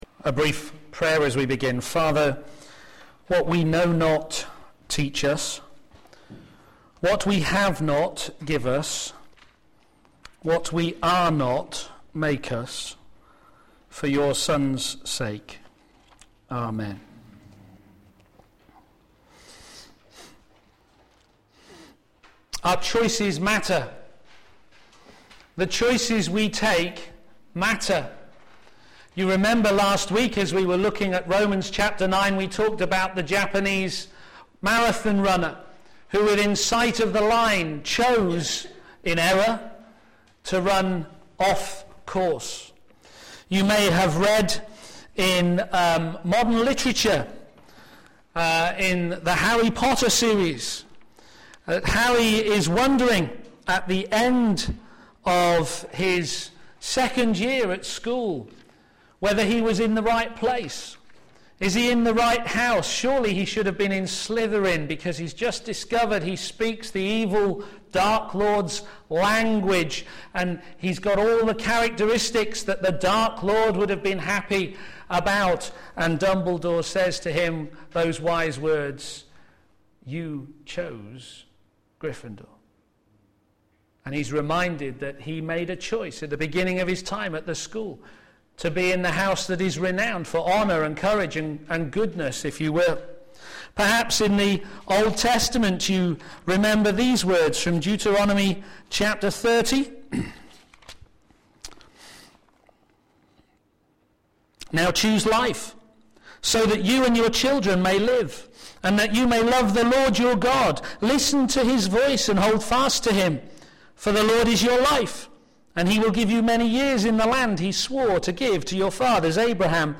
p.m. Service
God's righteousness revealed in Israel's need of the Gospel Sermon